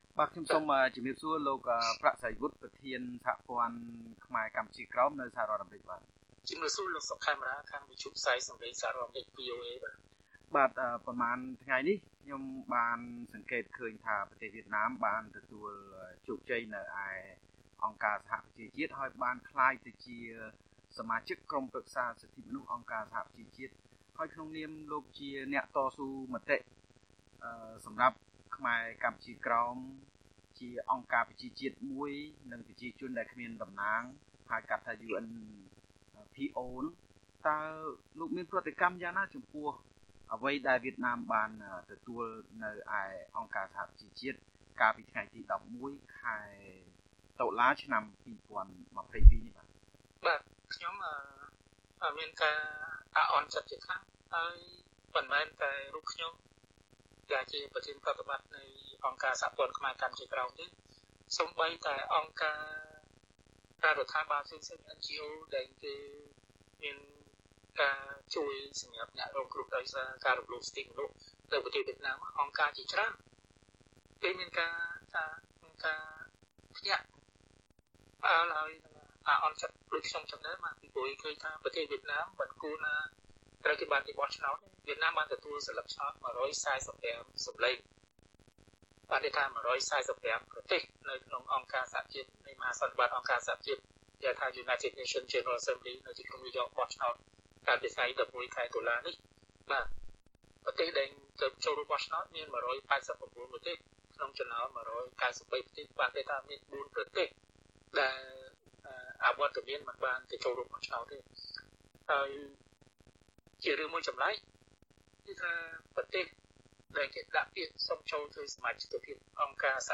បទសម្ភាសន៍ VOA៖